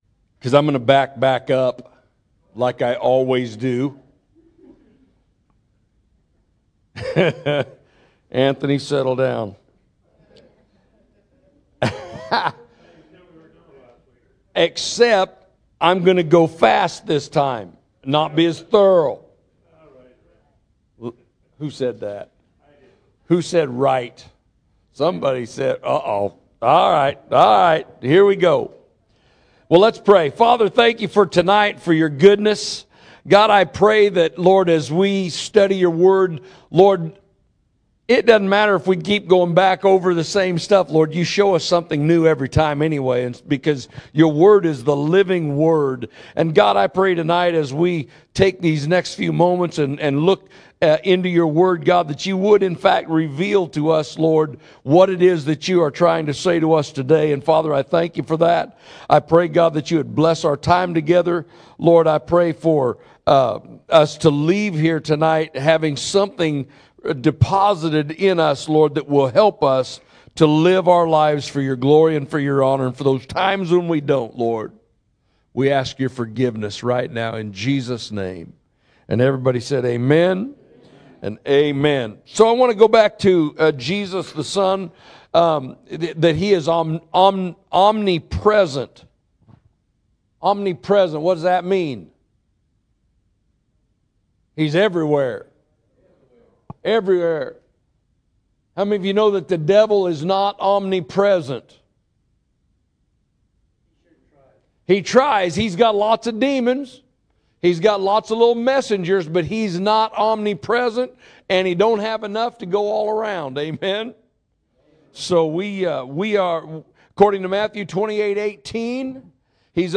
Sunday Morning Service July 7, 2024 – Life in the Spirit